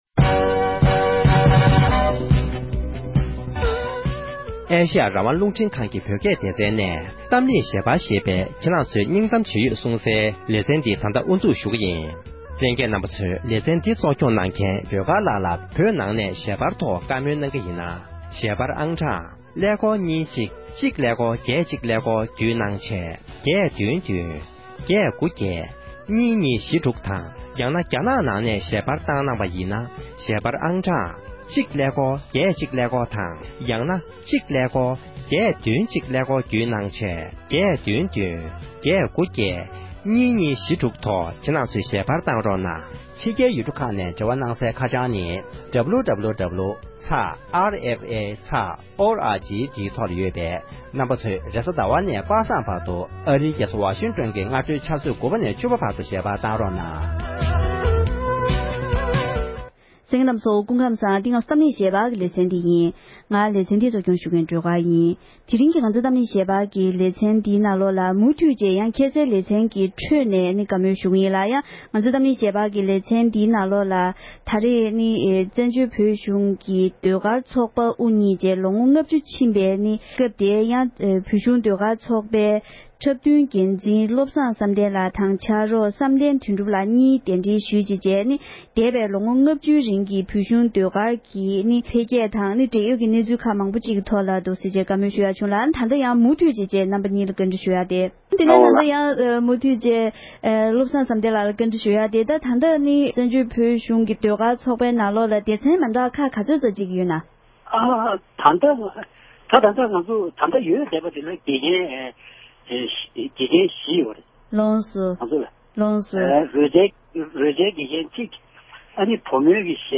བཀའ་འདྲི་ཞུས་པའི་ལེ་ཚན་གཉིས་པ་དེ་གསན་རོགས་གནོངས༎